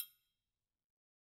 Triangle3-HitFM_v2_rr2_Sum.wav